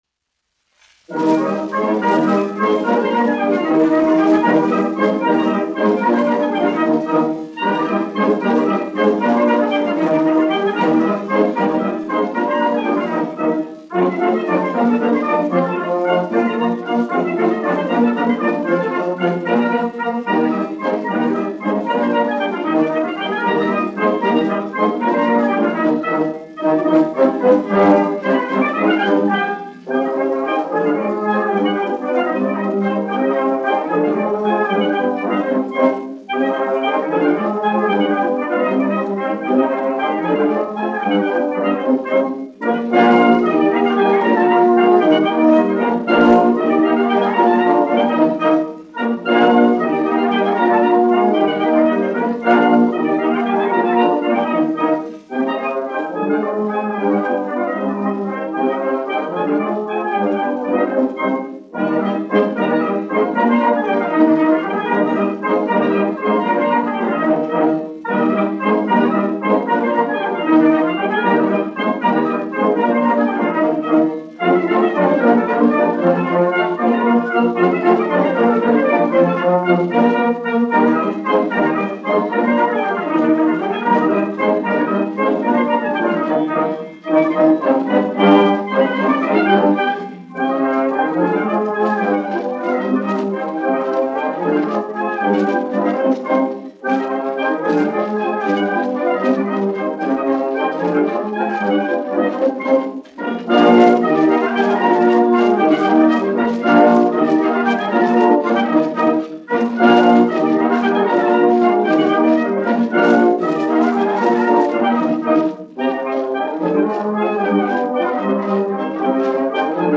1 skpl. : analogs, 78 apgr/min, mono ; 25 cm
Pūtēju orķestra mūzika
Mazurkas
Latvijas vēsturiskie šellaka skaņuplašu ieraksti (Kolekcija)